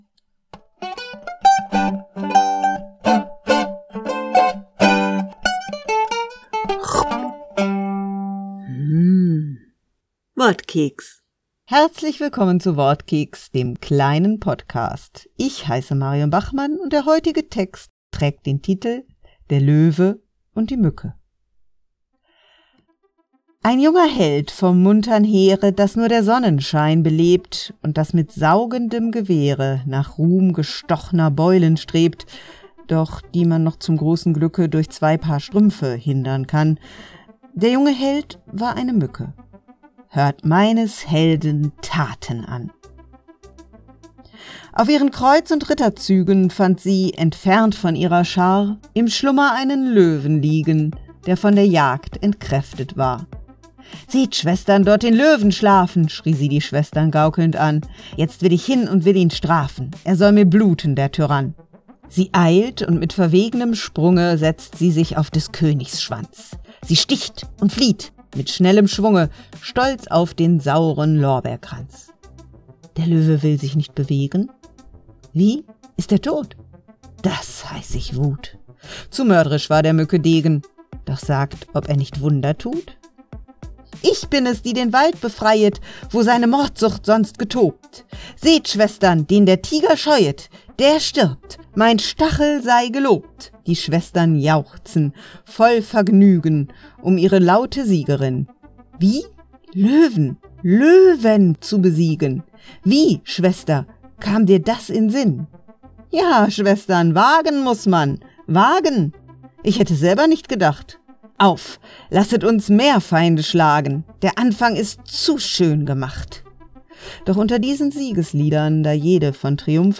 Wortkeks - die kleine Lesung
Music: slightly altered version; original by Mativve